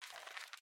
recycle звук очистки корзины на windows 7